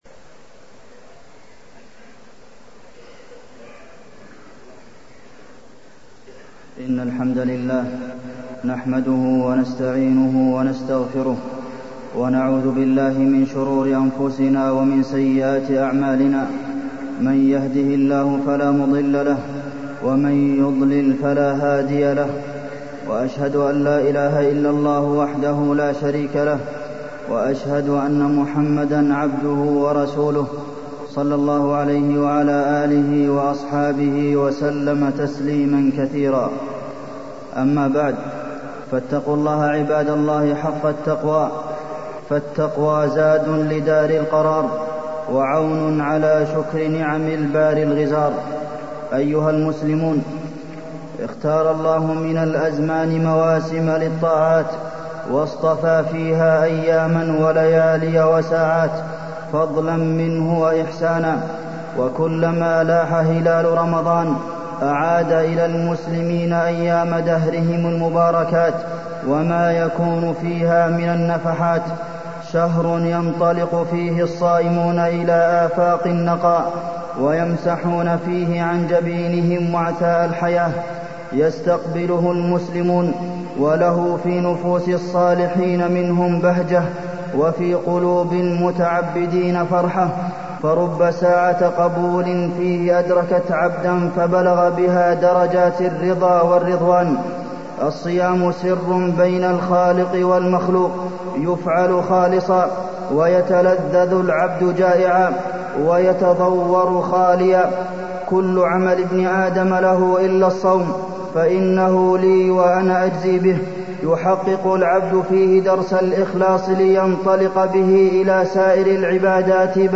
تاريخ النشر ٢٦ شعبان ١٤٢٣ هـ المكان: المسجد النبوي الشيخ: فضيلة الشيخ د. عبدالمحسن بن محمد القاسم فضيلة الشيخ د. عبدالمحسن بن محمد القاسم استقبال شهر رمضان